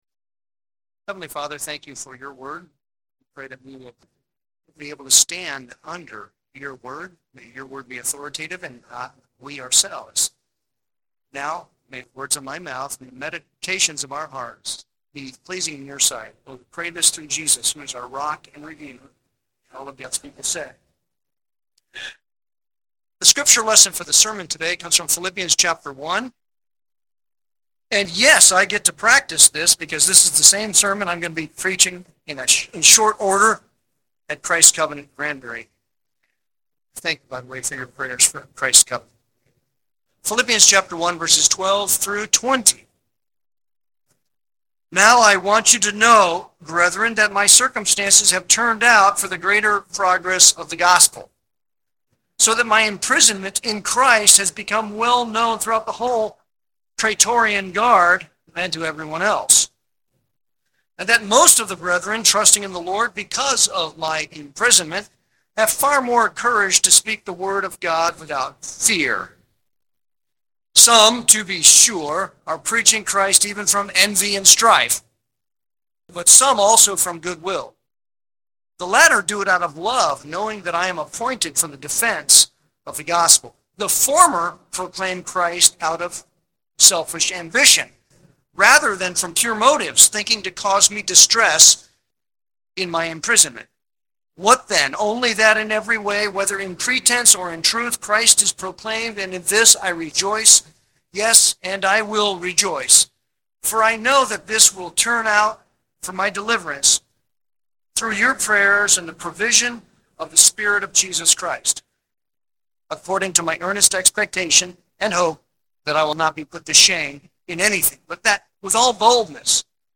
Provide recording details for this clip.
Audio Quality Note: We apologize for the poor quality of this audio. It was way worse, we did our best.